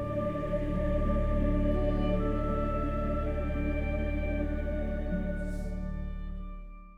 Questo è quello convertito da EAC3to: